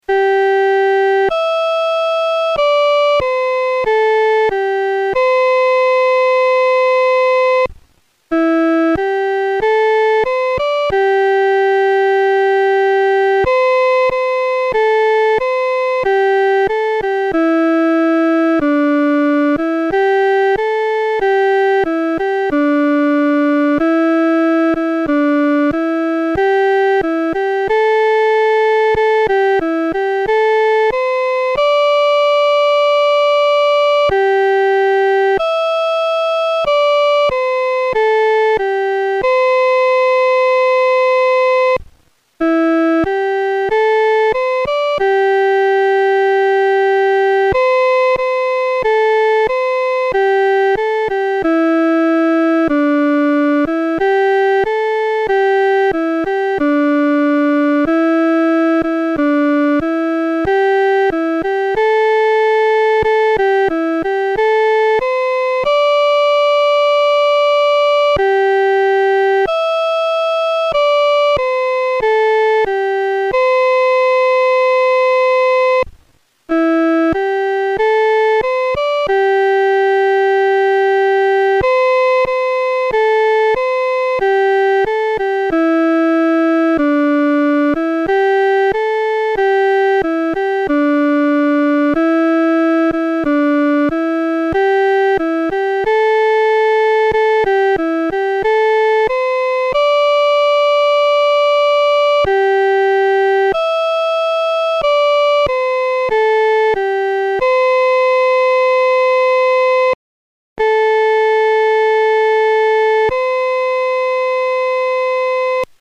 女高